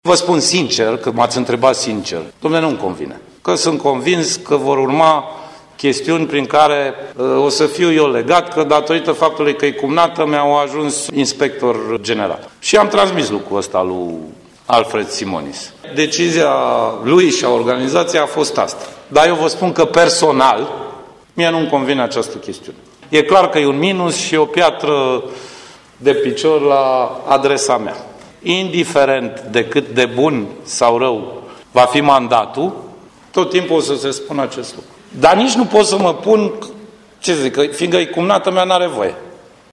Prim-vicepreședintele PSD, Sorin Grindeanu, a declarat că numirea cumnatei sale în funcția de inspector școlar general al județului Timiș nu îi convine.